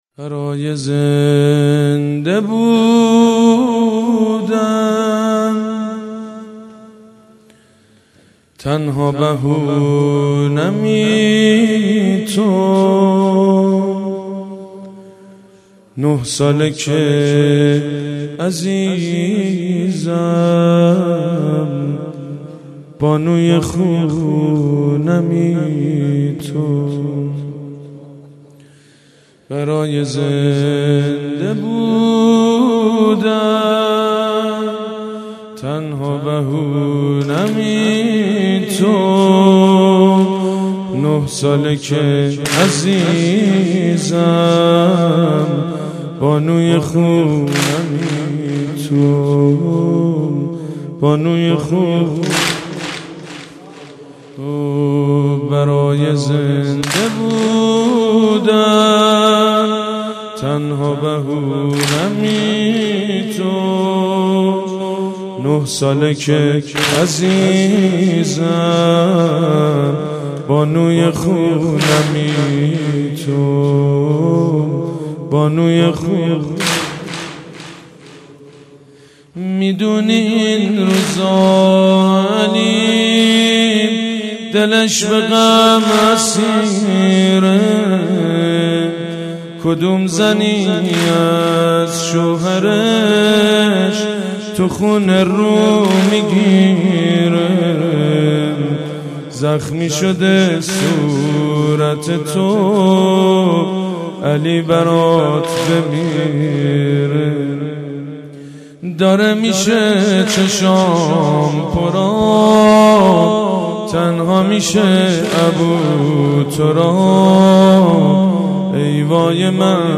مناسبت : شهادت حضرت فاطمه زهرا سلام‌الله‌علیها
قالب : سنگین